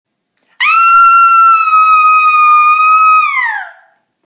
Download Screaming sound effect for free.
Screaming